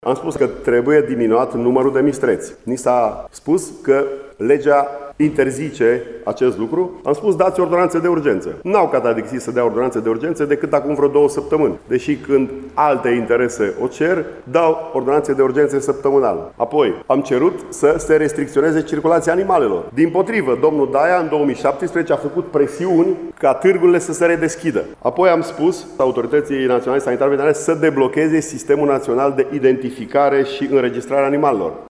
Deputatul PNL, Adrian Oros: